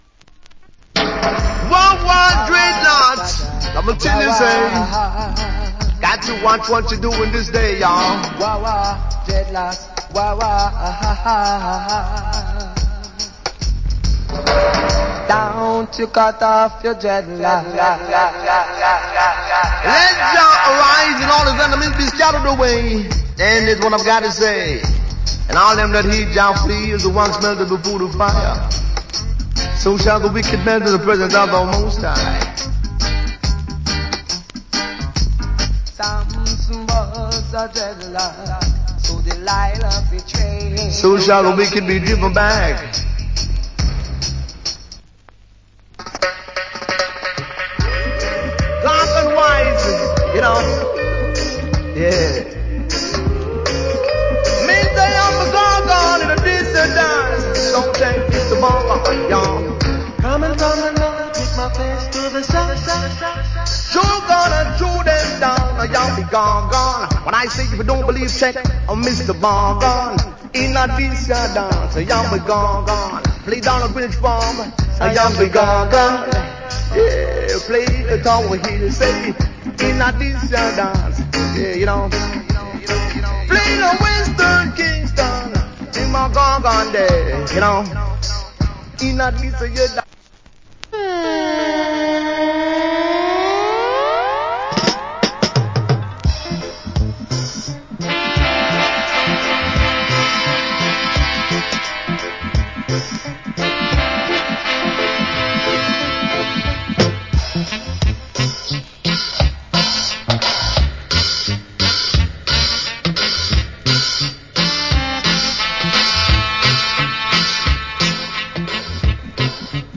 REGGAE
Side B Is Nice Dub Tracks.